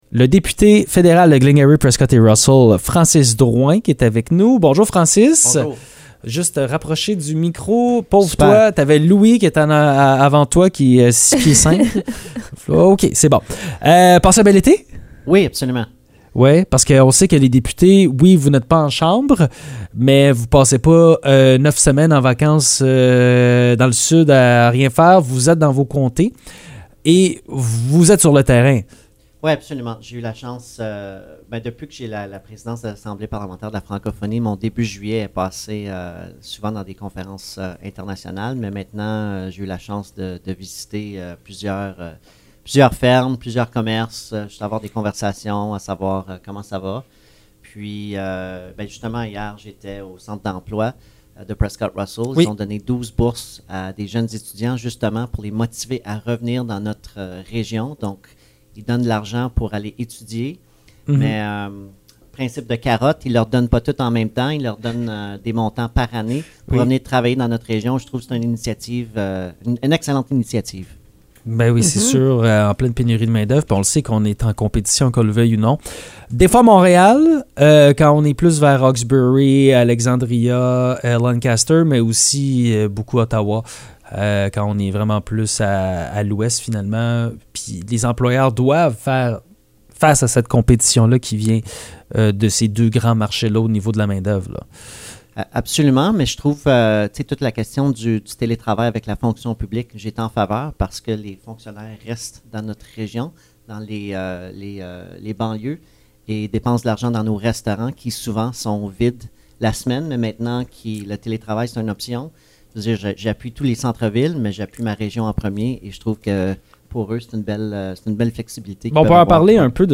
Francis Drouin, député fédéral de Glengarry-Prescott-Russell, était avec nous ce matin pour discuter de la crise du logement, de la rentrée parlementaire, de la montée des conservateurs dans les sondages et des projets à venir cet automne.